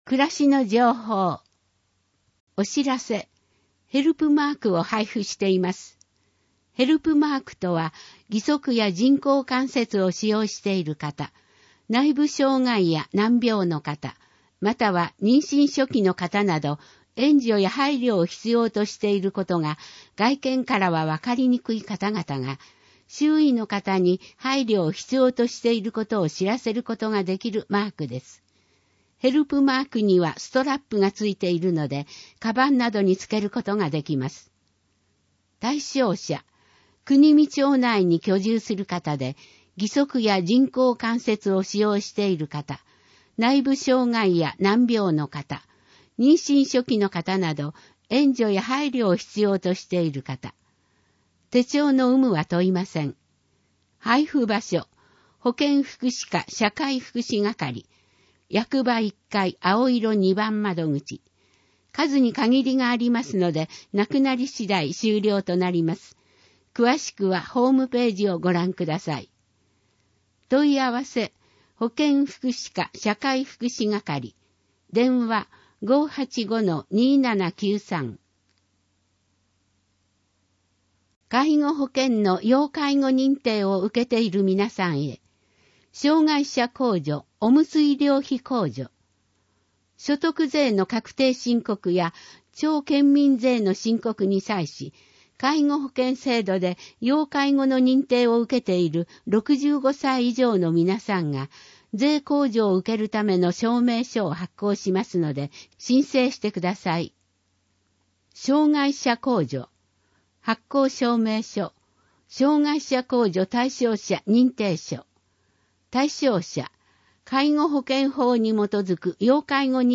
＜外部リンク＞ 声の広報 広報紙の内容を音声で提供しています。